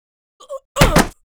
WAV · 112 KB · 單聲道 (1ch)